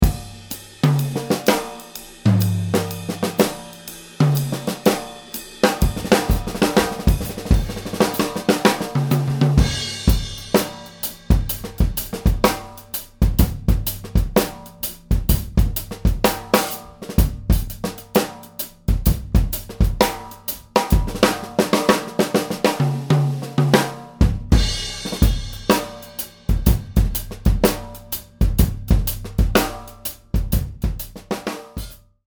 With close mics (588 KB)
The second mix adds close mics to the overheards. The small jazz kick now has some body, while the snare and toms have more presence.
All sound files were mixed without any EQ and without any processing other than limiting and MP3 conversion.
without-room.mp3